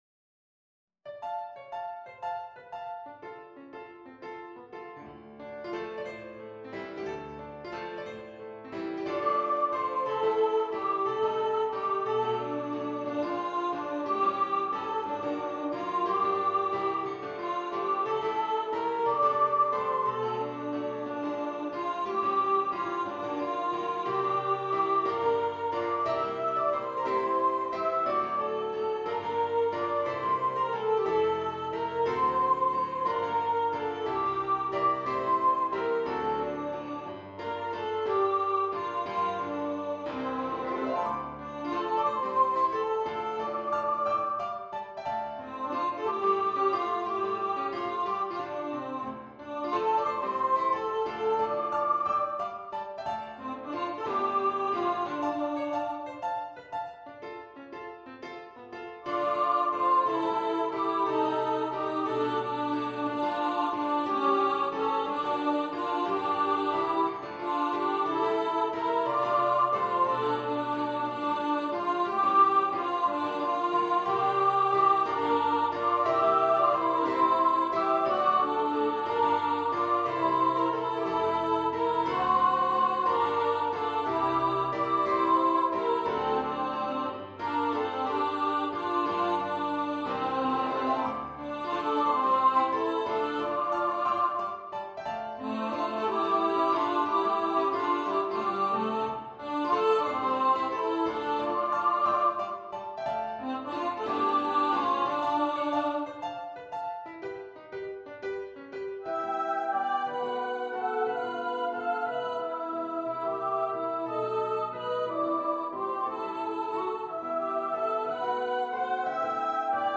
for upper voice choir
A lively and very rhythmic setting
two part upper voice choir (SA) and piano.